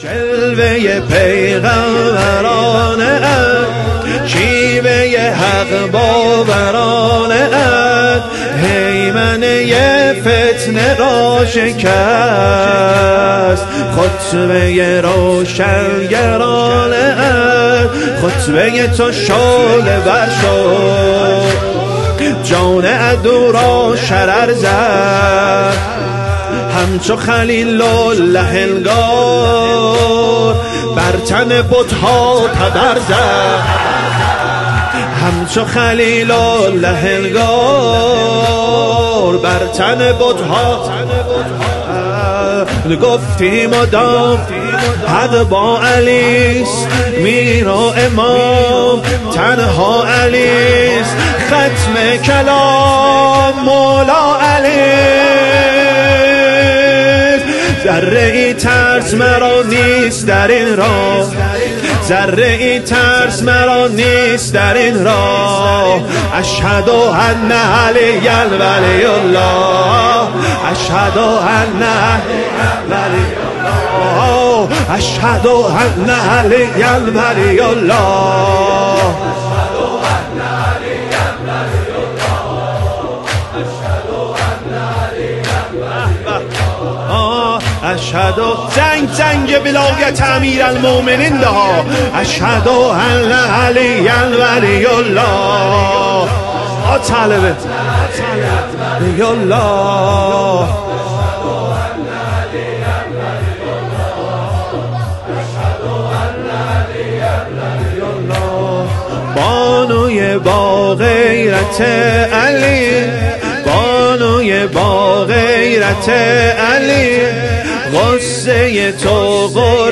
زمینه | جلوه پیغمبرانه ات
سینه زنی زمینه
ایام فاطمیه دوم - شب اول